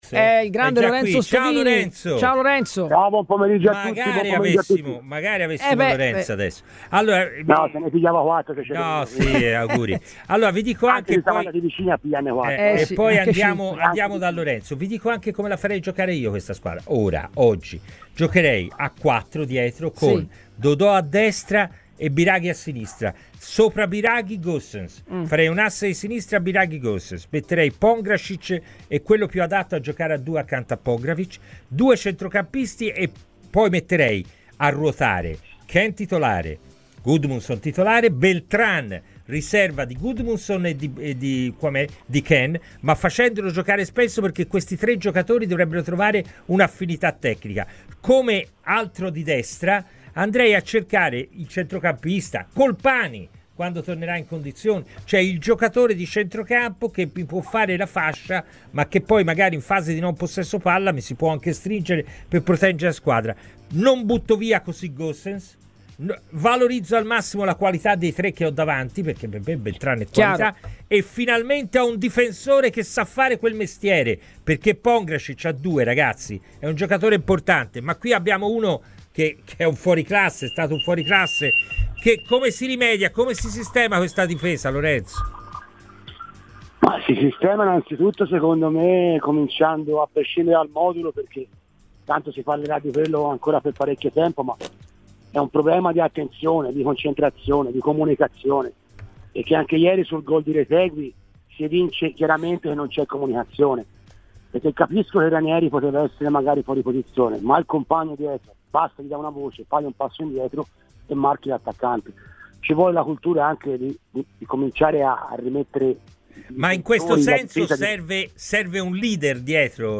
ex difensore e tifoso viola, è intervenuto ai microfoni di Radio FirenzeViola durante la trasmissione "Palla al centro" parlando dei problemi difensivi dei viola visti nella partita di ieri contro l'Atalanta